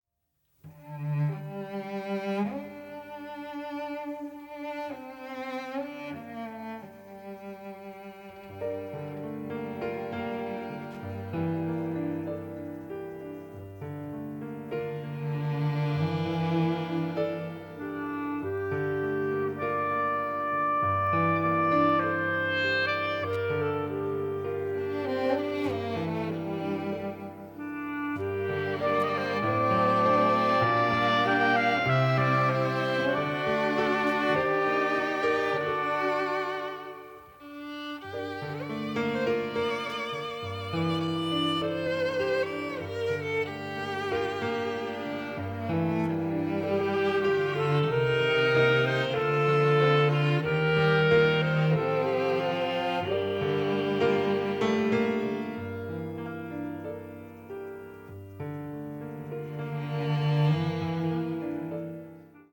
(Original Score)